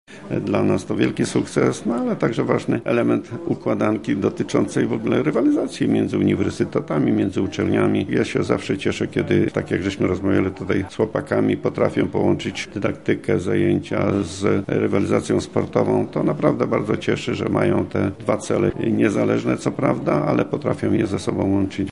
-Chyba nie liczyliśmy, że aż tak wysoko zajdziecie – mówi Rektor Michałowski.